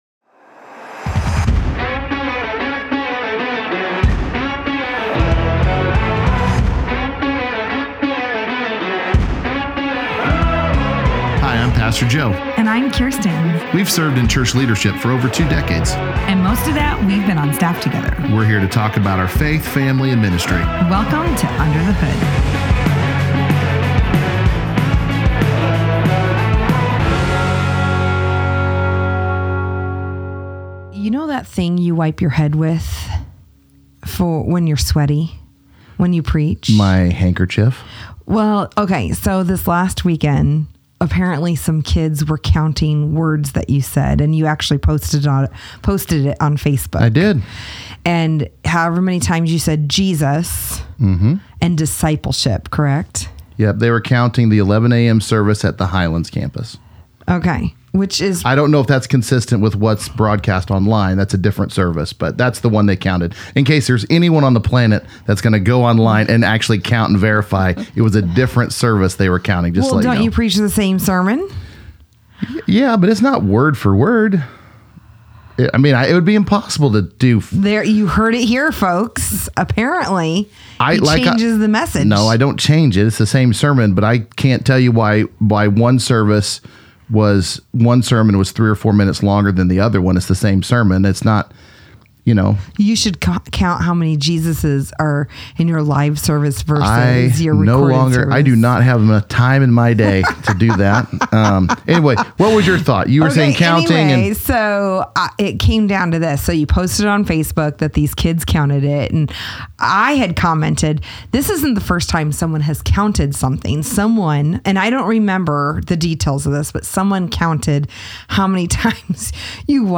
Then they expand on last weekend's sermon with an in-depth conversation about discipleship.